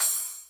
kanye hats_23-03.wav